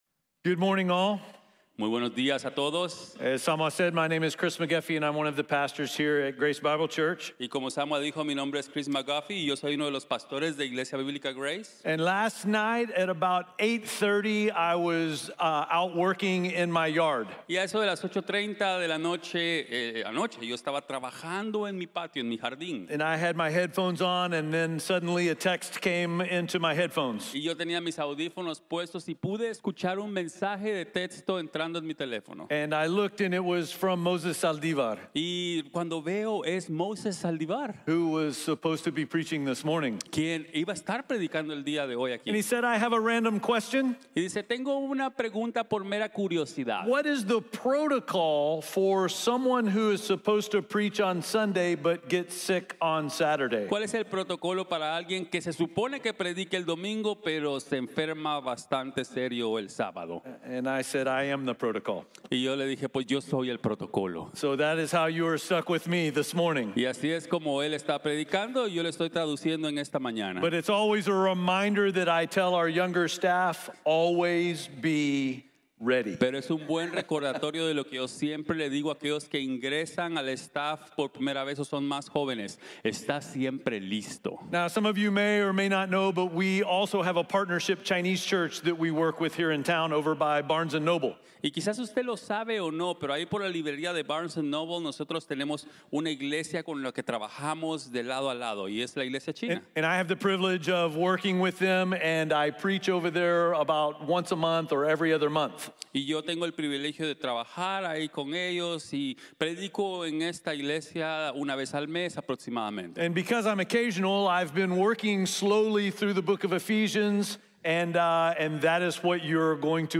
Additional Sermons